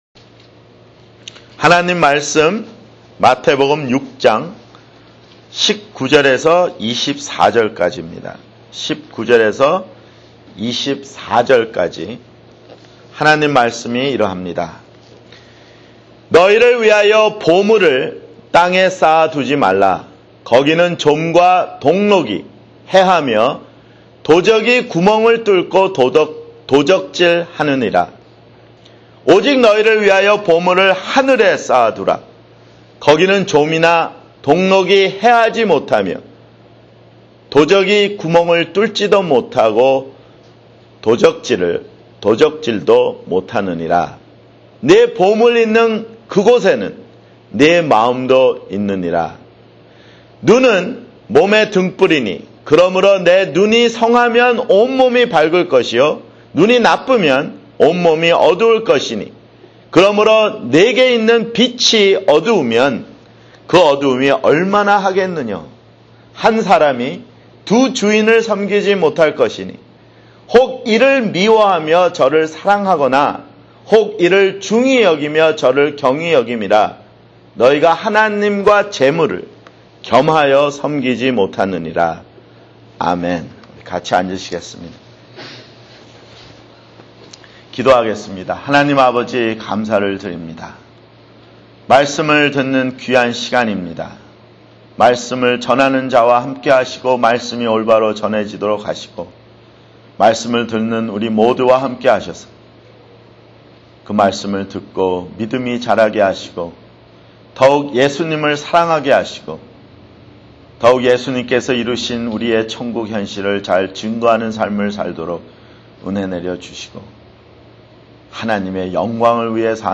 [주일설교] 마태복음 (41)